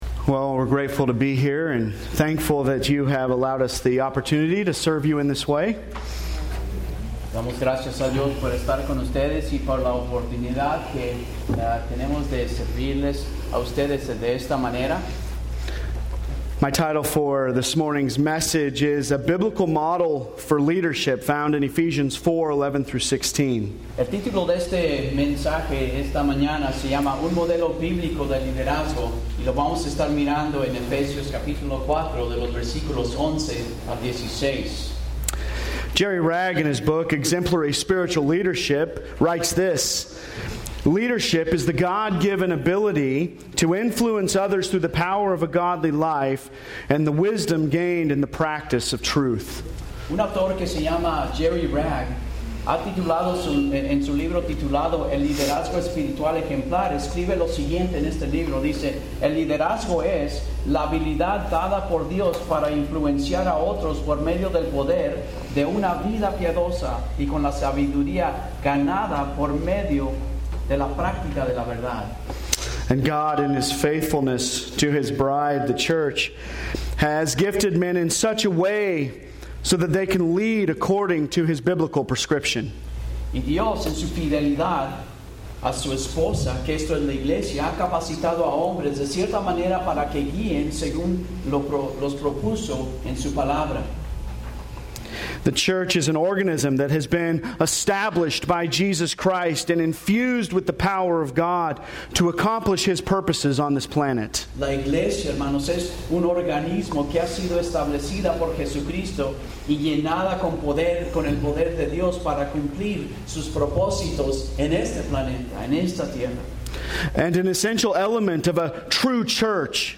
Sermons Mexico Pastors Conference - 2019